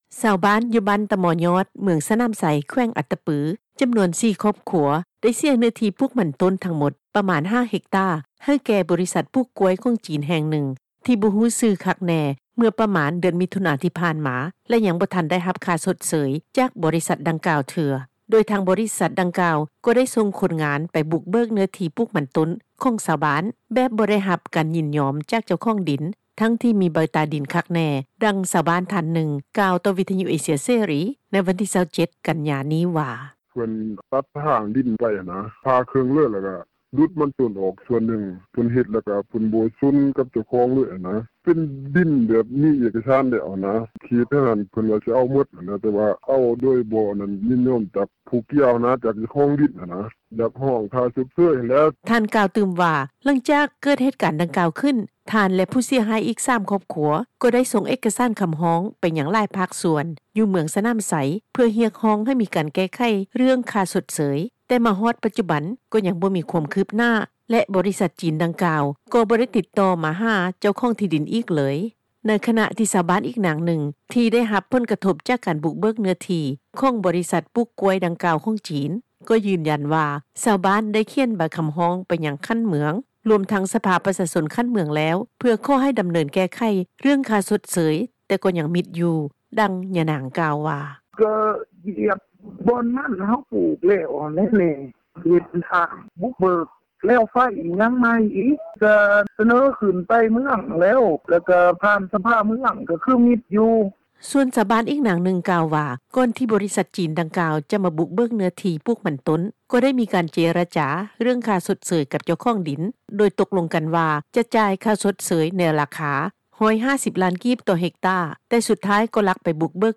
ນັກຂ່າວ ພົລເມືອງ
ດັ່ງ ຊາວບ້ານ ທ່ານນຶ່ງ ກ່າວຕໍ່ວິທຍຸ ເອເຊັຽ ເສຣີ ໃນວັນທີ່ 27 ກັນຍາ ນີ້ວ່າ: